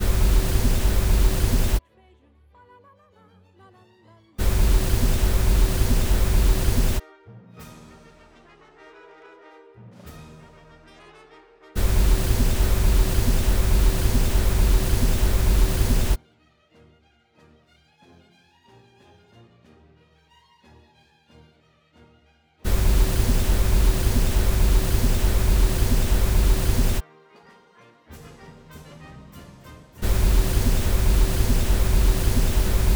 symfoniorkester